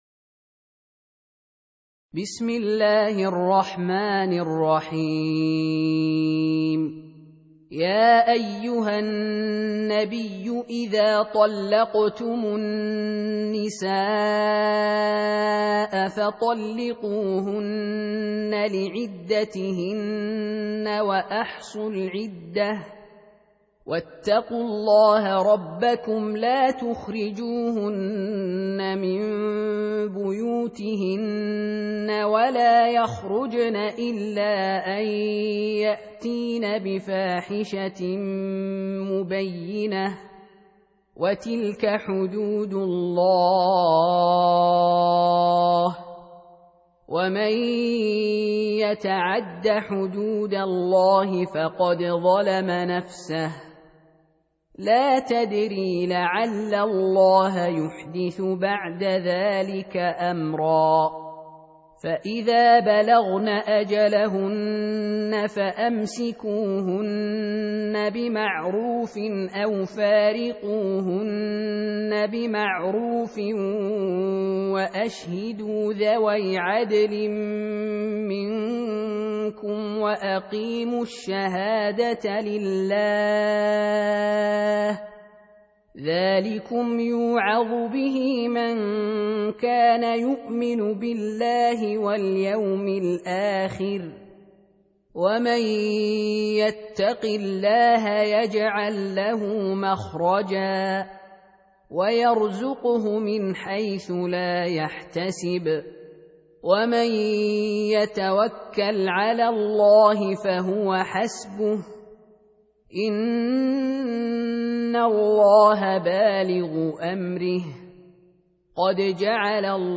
Surah Repeating تكرار السورة Download Surah حمّل السورة Reciting Murattalah Audio for 65. Surah At-Tal�q سورة الطلاق N.B *Surah Includes Al-Basmalah Reciters Sequents تتابع التلاوات Reciters Repeats تكرار التلاوات